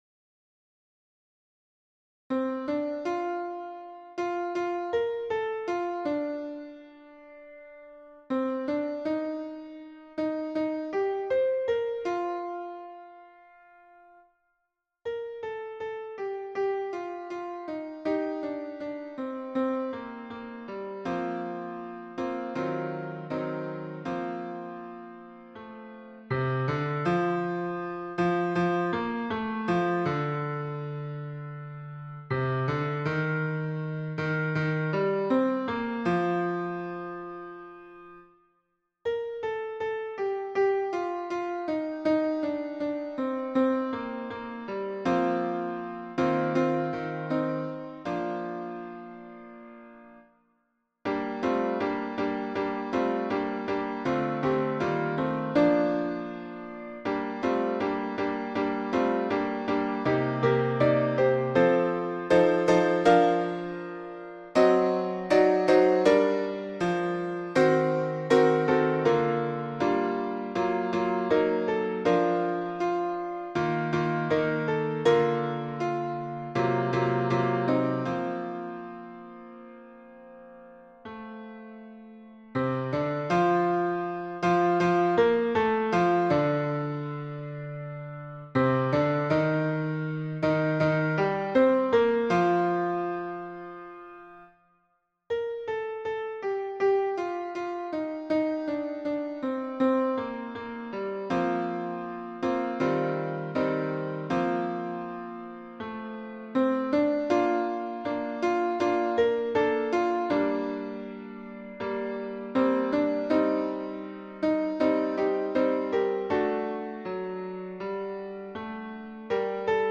MP3 version piano
Tutti